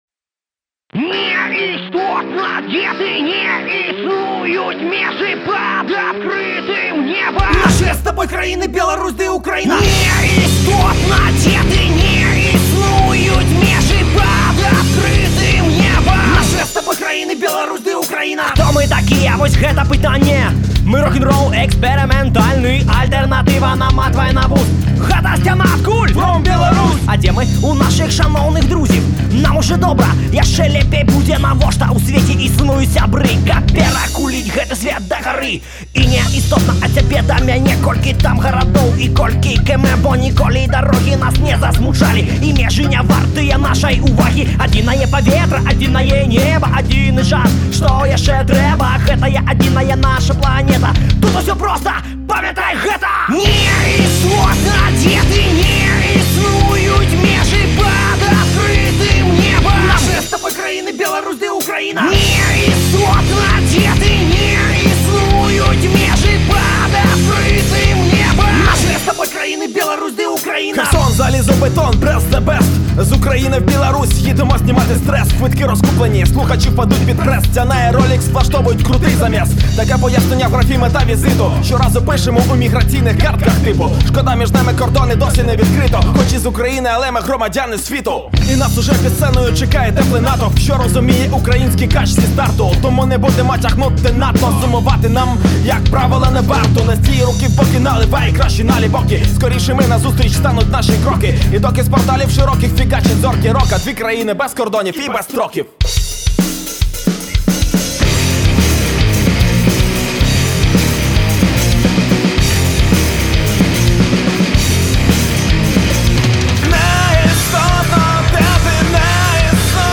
беларуска-ўкраінскага тандэма гуртоў